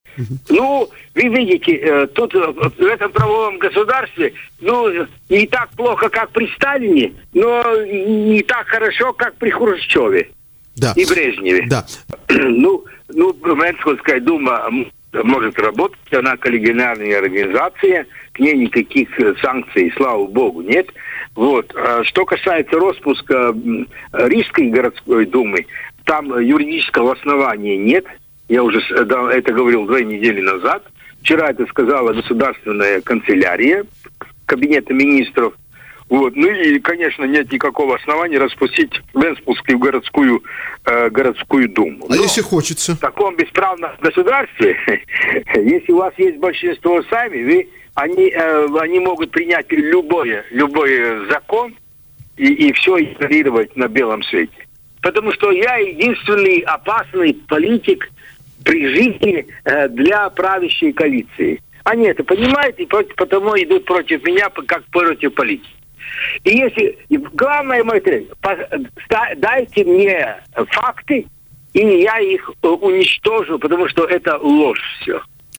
Главное мое требование – дайте мне факты и я их уничтожу, потому что это все ложь», - сказал Лембергс в эфире радио Baltkom.